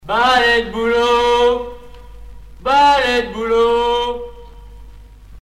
Mémoires et Patrimoines vivants - RaddO est une base de données d'archives iconographiques et sonores.
Cris de rue d'un marchand de balais de bouleau